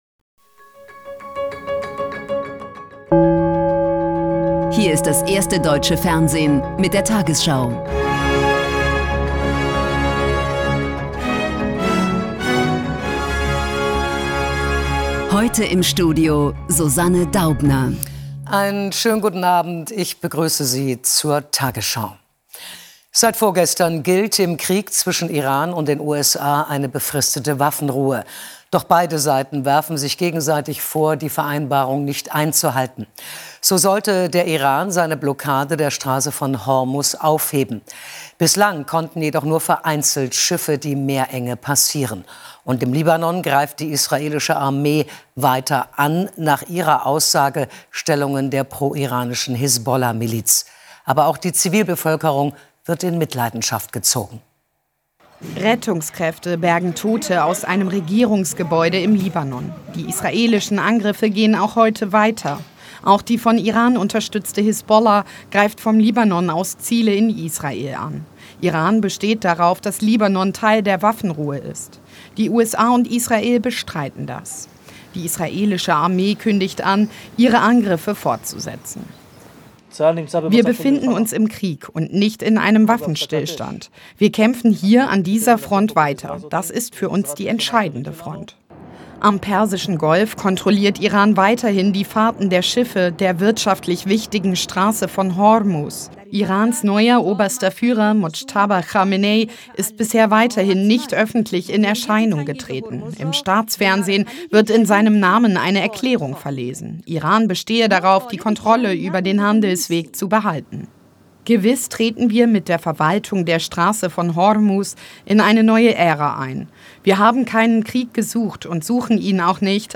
tagesschau 20:00 Uhr, 10.04.2026 ~ tagesschau: Die 20 Uhr Nachrichten (Audio) Podcast